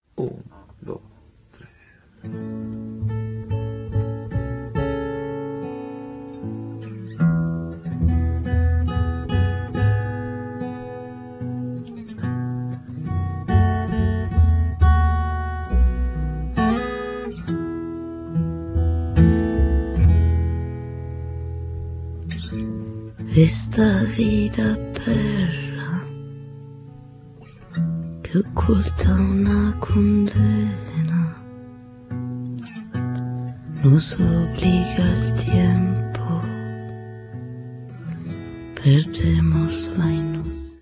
śpiew / voz, akordeon / acordeón
gitara / guitarra, saksofon / saxo, charango, śpiew /coros
trąbka / trompeta
saksofon altowy / saxo alto
gitara elektryczna / guitarra eléctrica, mandolina, charango